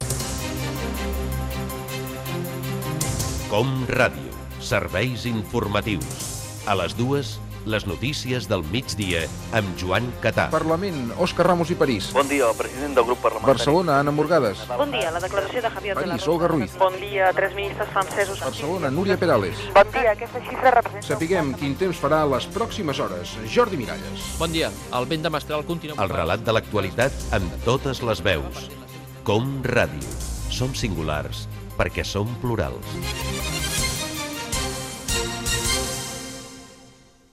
Promoció del programa
Informatiu